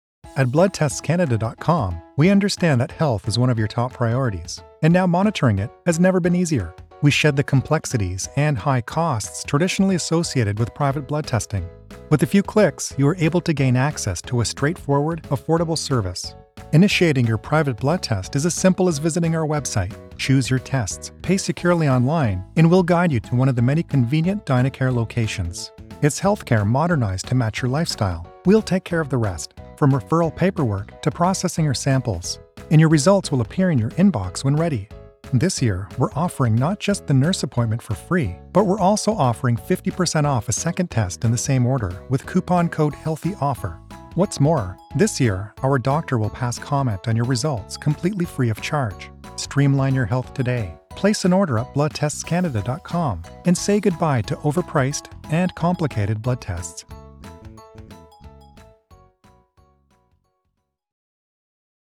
Male
Adult (30-50)
A friendly, warm and relatable voice to put a human touch on your project. Studio-quality voiceovers that sound like a real conversation, not something out of a can.
Television Spots